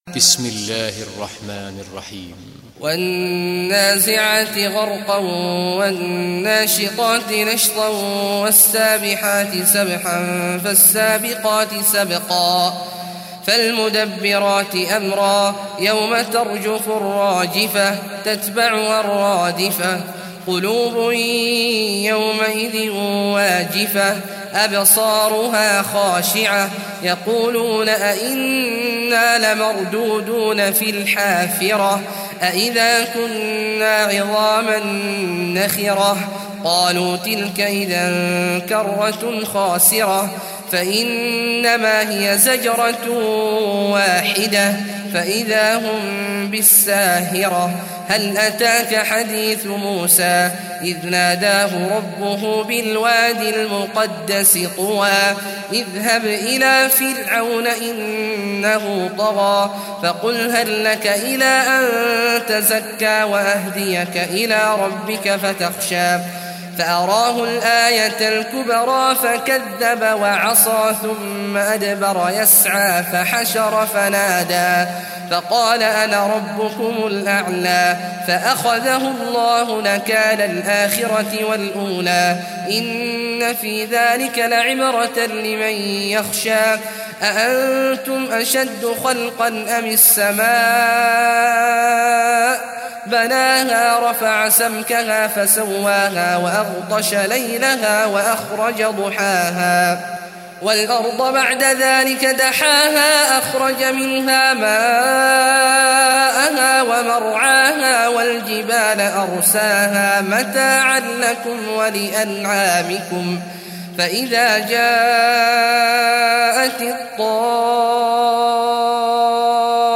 Surah An Naziat Recitation by Sheikh Awad Juhany
Surah An Naziat, listen or play online mp3 tilawat / recitation in Arabic in the beautiful voice of Sheikh Abdullah Awad al Juhany.